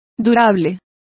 Complete with pronunciation of the translation of durable.